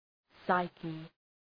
Προφορά
{‘saıkı}